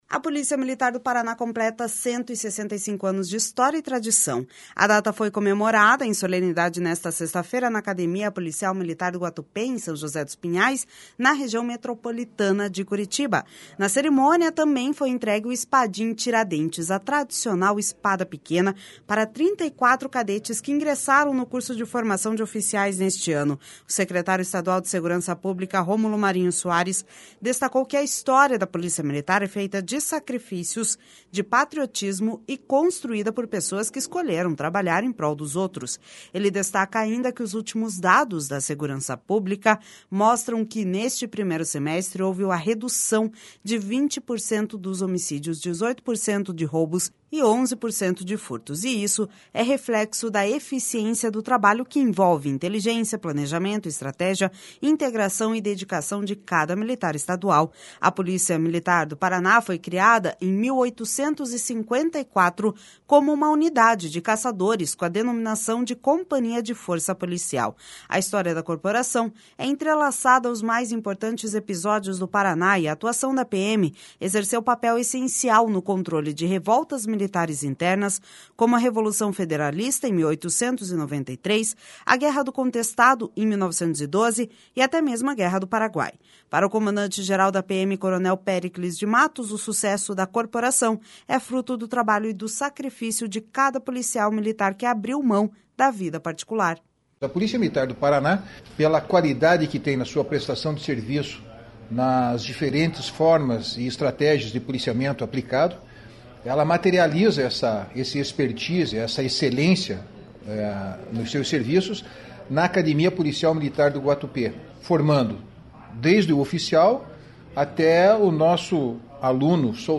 A data foi comemorada em solenidade nesta sexta-feira, na Academia Policial Militar do Guatupê, em São José dos Pinhais, na Região Metropolitana de Curitiba. Na cerimônia, também foi entregue o Espadim Tiradentes, a tradicional espada pequena, para 34 cadetes que ingressaram no Curso de Formação de Oficiais neste ano.